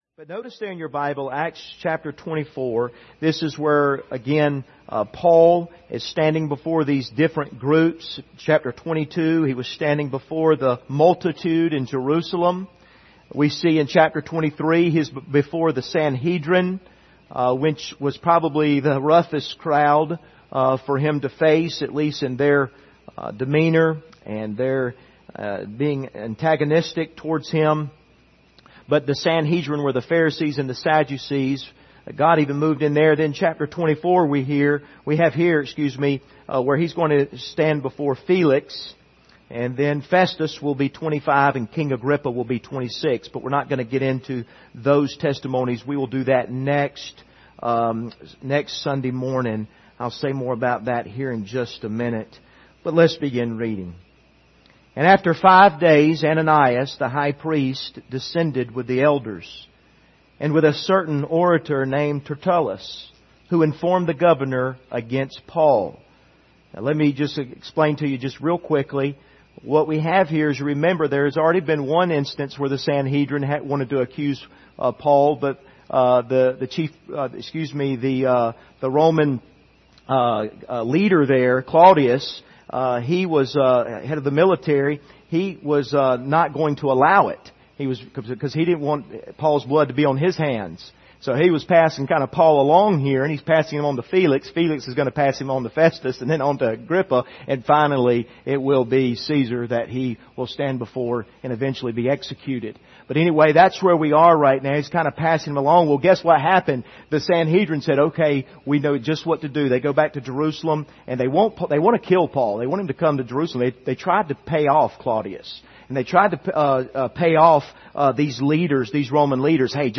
Passage: Acts 24:1-27 Service Type: Sunday Evening